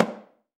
AFRO.TAMB8-S.WAV